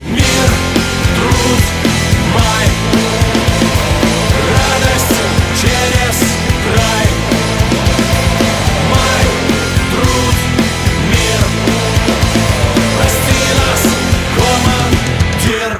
• Качество: 192, Stereo
Строгая и в тоже время забавная песня.